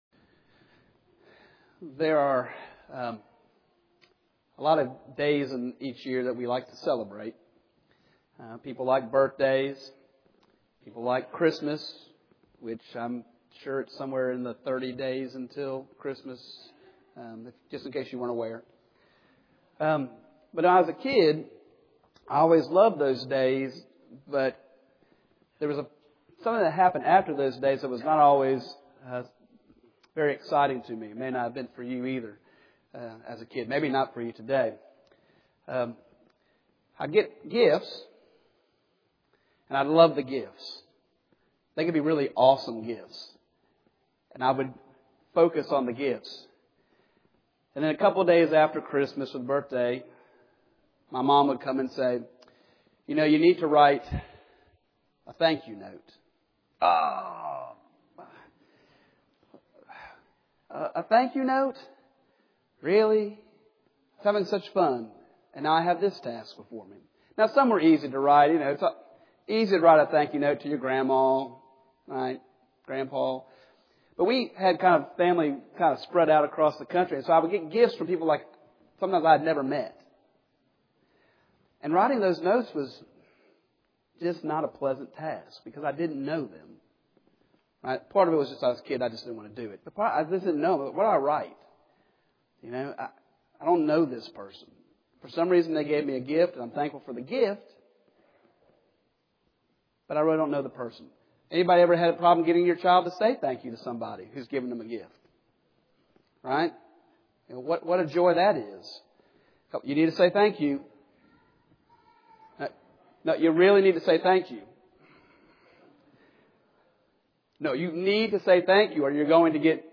Psalm 16:1-11 Service Type: Sunday Morning https